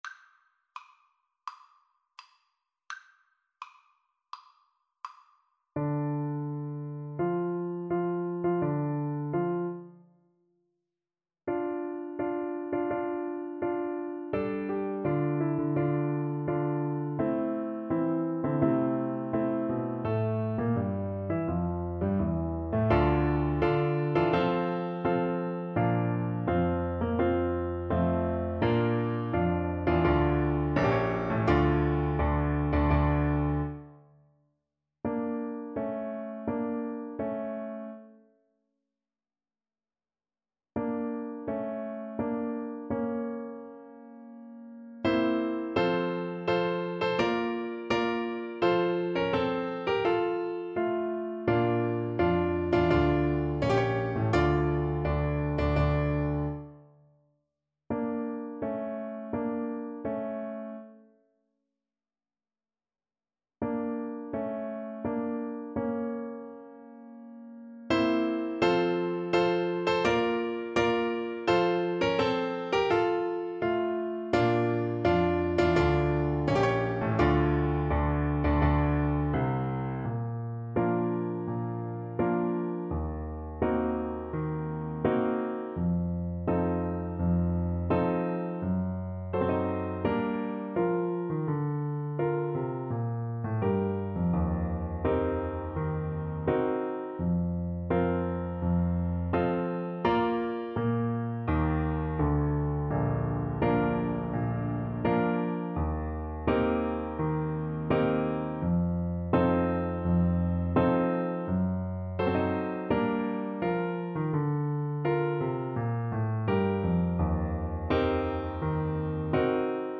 Play (or use space bar on your keyboard) Pause Music Playalong - Piano Accompaniment Playalong Band Accompaniment not yet available reset tempo print settings full screen
Tempo di Marcia =84
D minor (Sounding Pitch) (View more D minor Music for Flute )